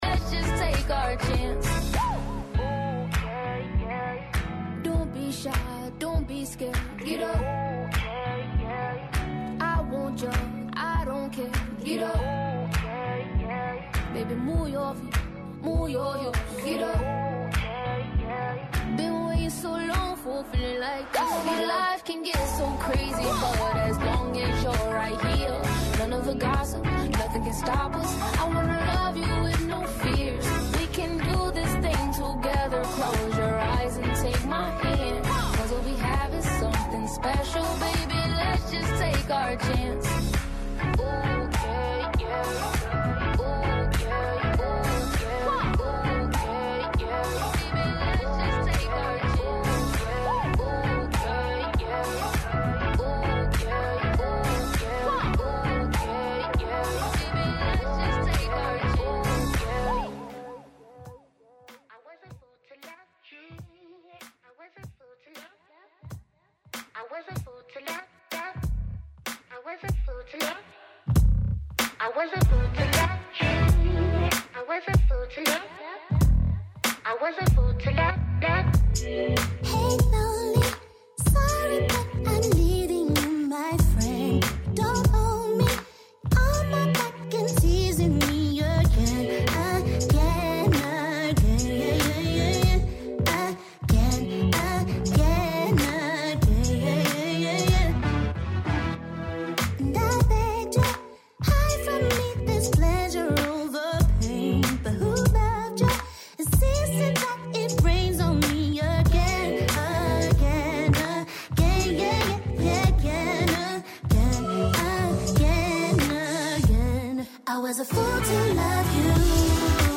On this show, you’ll hear the recent news, personal experiences and a diverse selection of music.